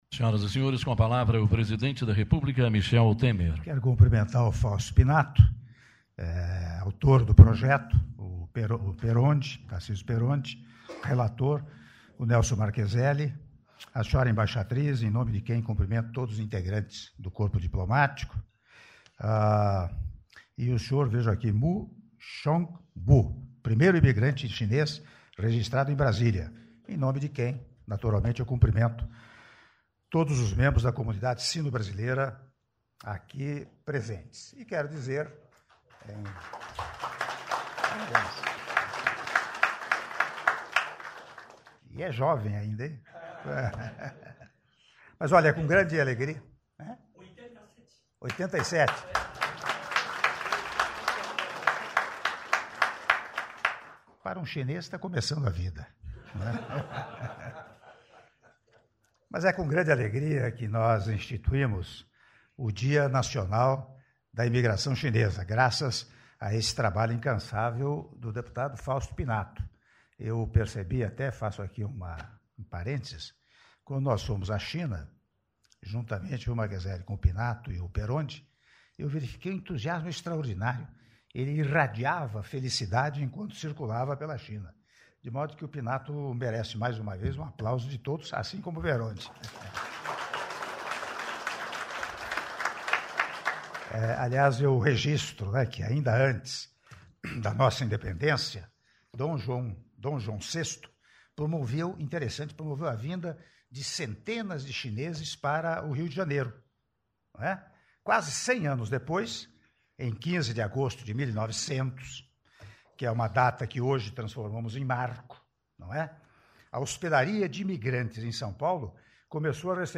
Áudio do discurso do Presidente da República, Michel Temer, durante Cerimônia do Projeto de Lei que Institui no Calendário Brasileiro o Dia Nacional da Imigração Chinesa - (06min04s)